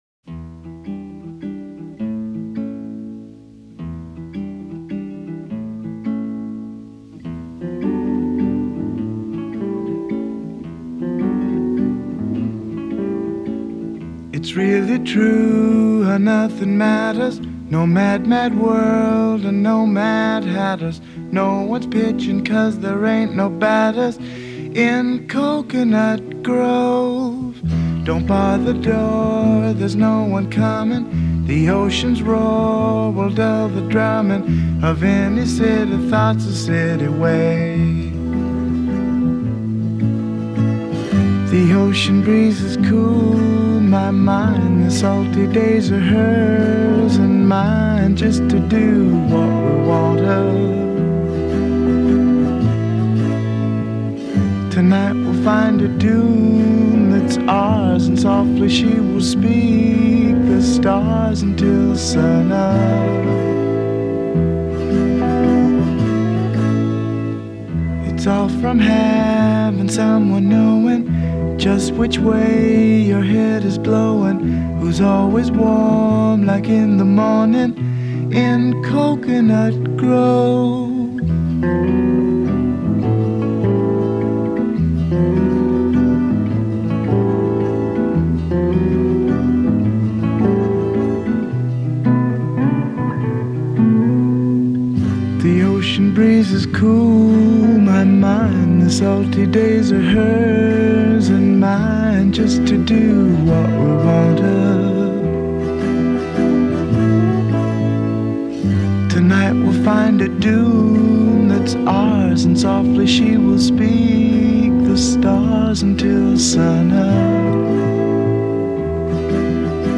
close-miked and breathy